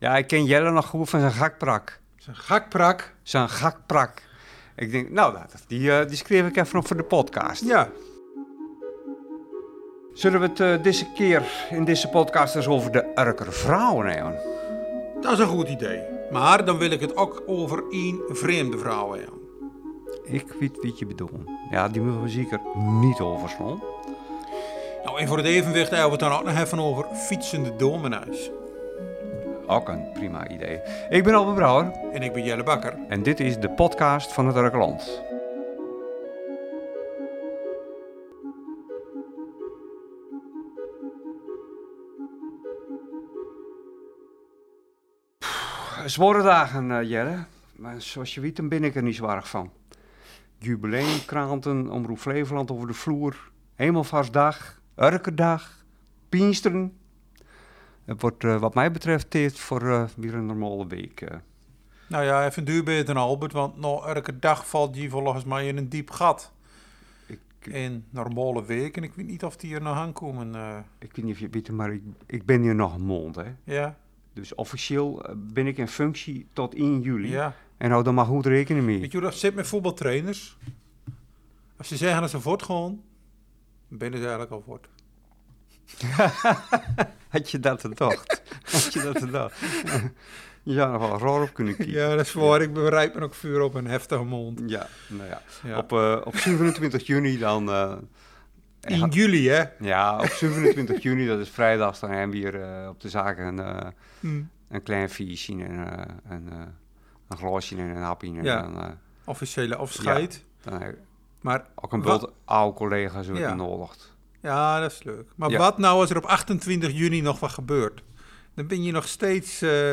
De redactie van Het Urkerland was afgelopen dinsdag te vinden op een plek waar de hele dag door, zes dagen per week vele Urkers in- én uitlopen: de plaatselijke Albert Heijn.